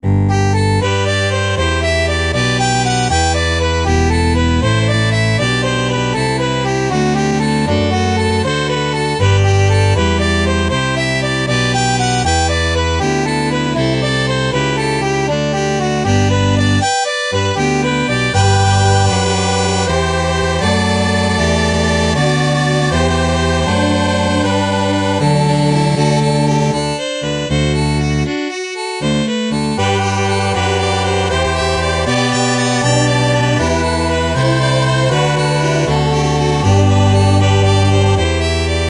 クラシック